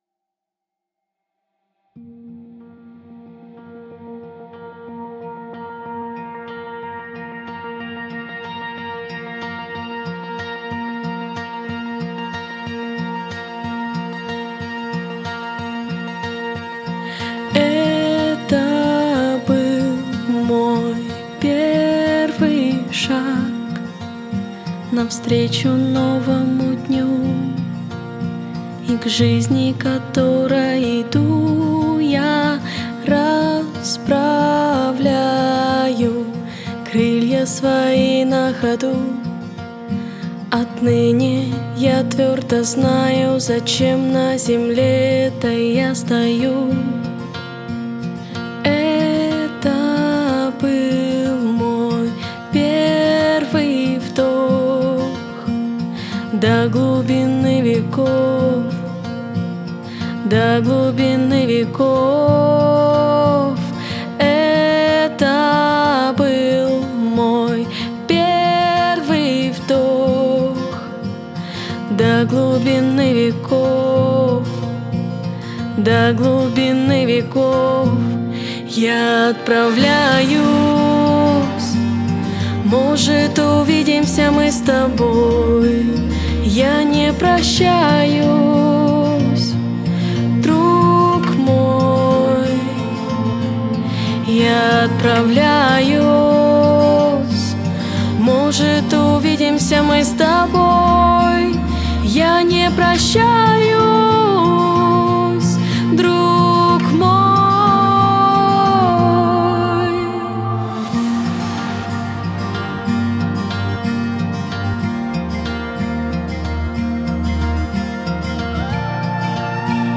849 просмотров 291 прослушиваний 44 скачивания BPM: 123